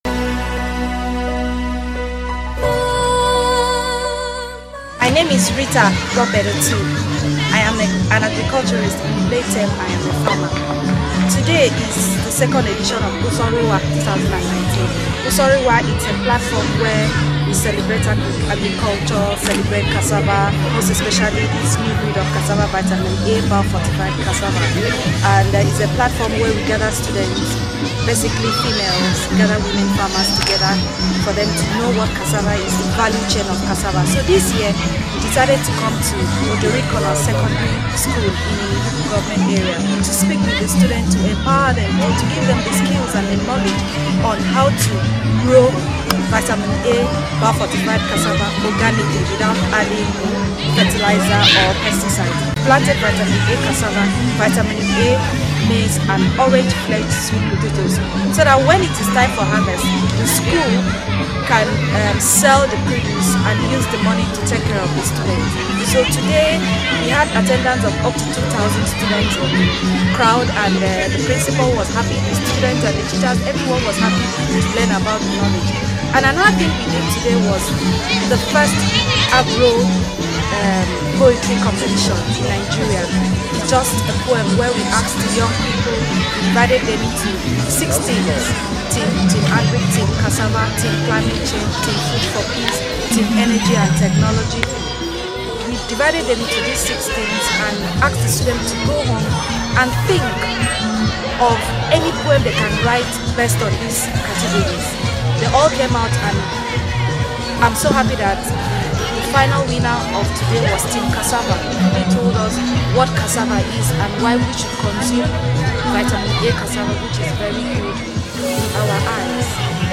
casava-festival-2019.mp3